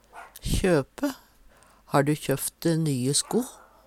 DIALEKTORD PÅ NORMERT NORSK kjøpe kjøpe Infinitiv Presens Preteritum Perfektum kjøpe kjøper kjøffte kjøfft Eksempel på bruk Har du kjøfft de nye sko?